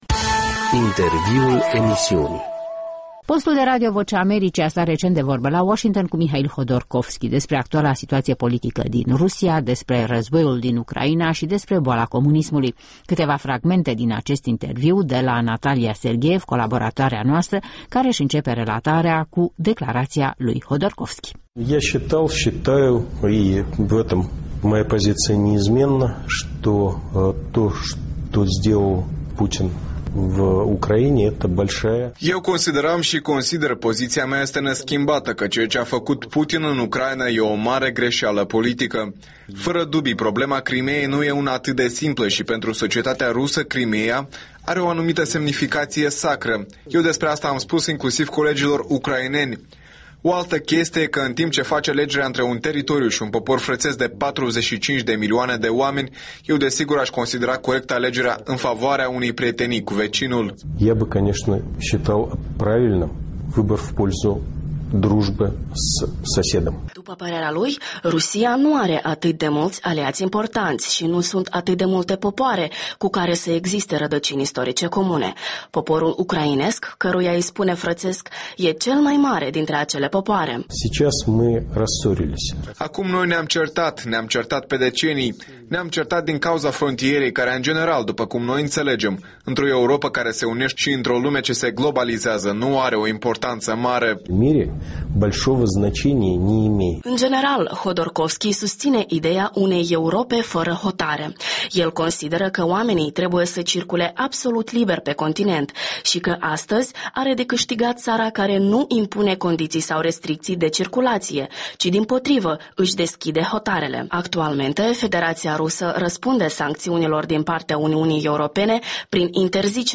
Un interviu la Washington cu Mihail Hodorkovski